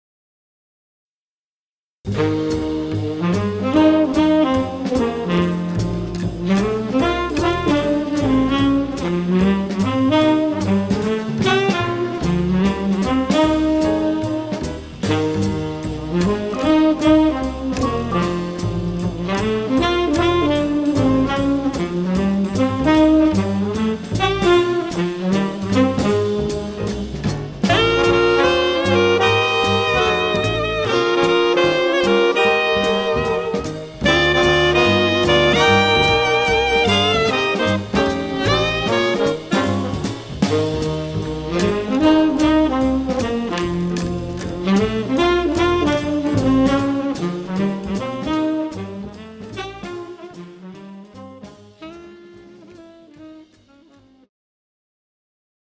The Best In British Jazz
Recorded at Red Gables Studio.